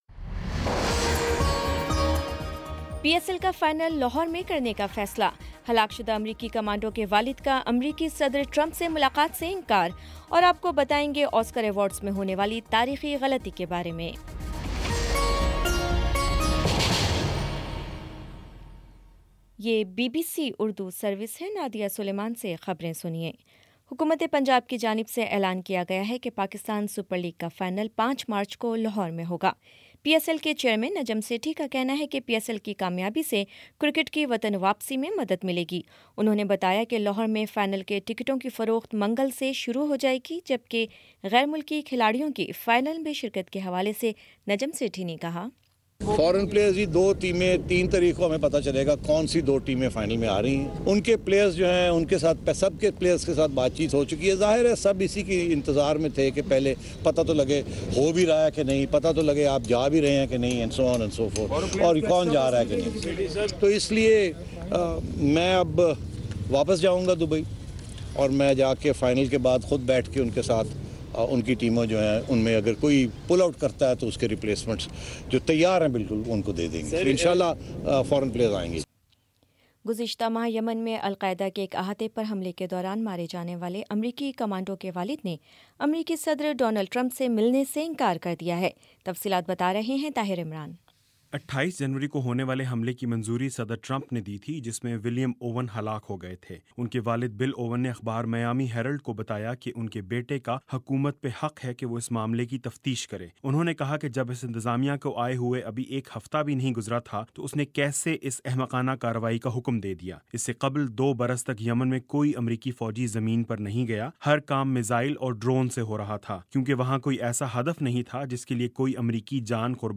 فروری 27 : شام سات بجے کا نیوز بُلیٹن